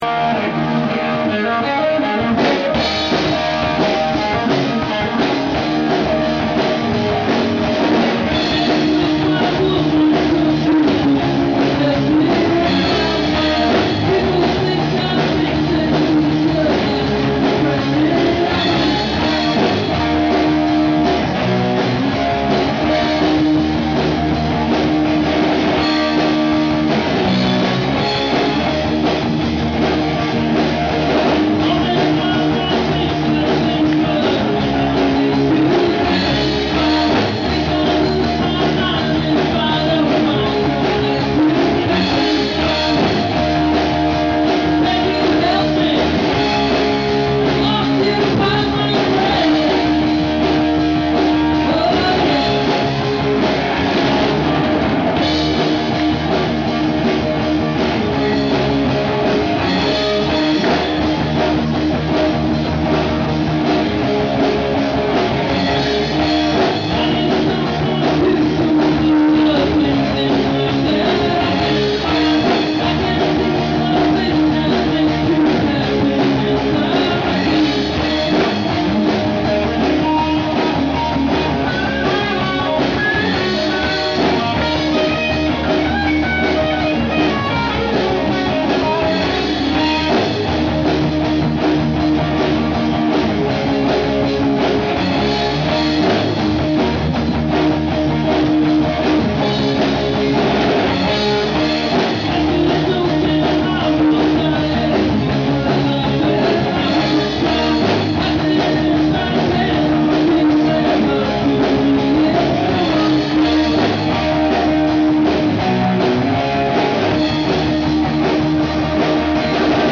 after which they attended my band playing at a local bar.
Straight-Up Rock & Roll!!
vocals